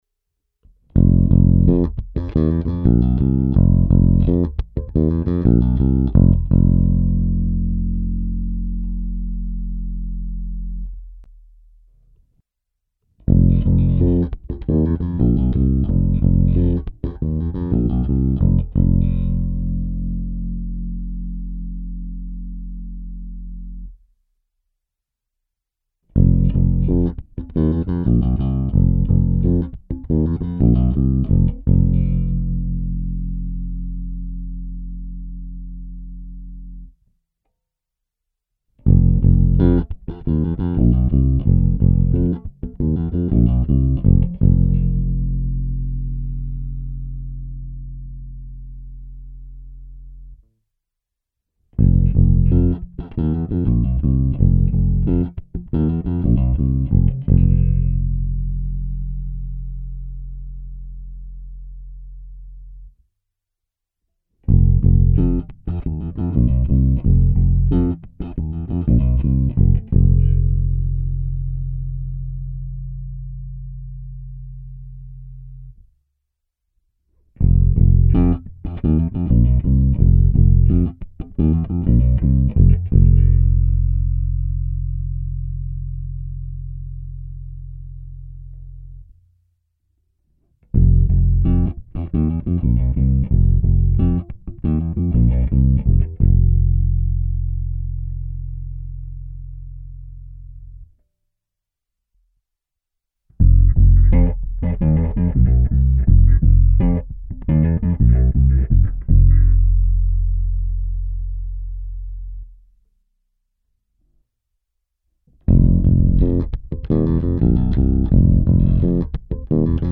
Ovšem upřímně řečeno, ze zvuku jako takového nijak nadšený nejsem, i podladění o půl tónu zvuk slyšitelně degraduje. Nemá takovou průraznost a čistotu, navíc u dlouhých tónů trochu chvěje.
Nahrál jsem ukázku s baskytarou Ernie Ball Music Man StingRay 5 v tónině F, kdy první část je čistý zvuk baskytary, pak jsem zvuk dropoval postupně o půltón a hrál fyzicky na base vždy o pražec výše tak, aby byla zachována původní tónina a vy jste mohli posoudit, jak velký vliv má daný stupeň podladění na výsledný zvuk. V ukázce je po čistém zvuku tedy sedm kroků po půltónech, pak podladění o oktávu a nakonec klasický oktáver.
Když se zaměříte na konce jednotlivých ukázek, kdy doznívá poslední tón, všimnete si u efektovaného zvuku kolísání hlasitosti zvuku.